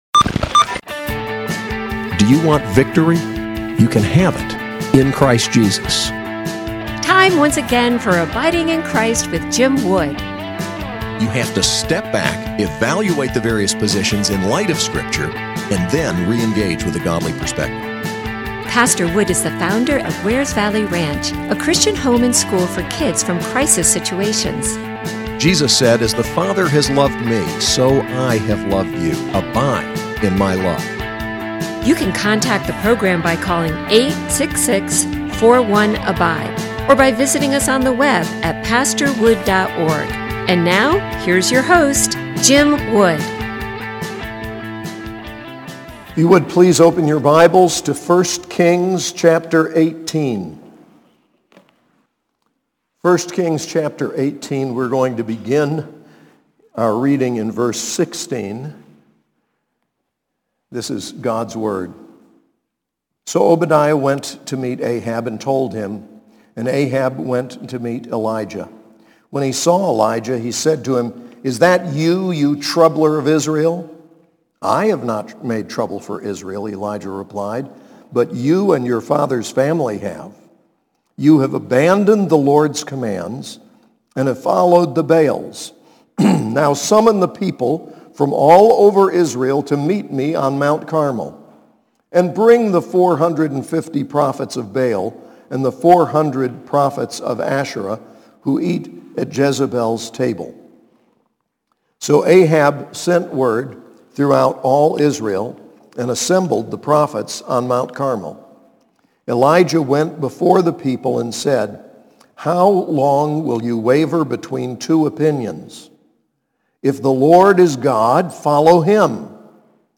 SAS Chapel: 1 Kings 18:16-46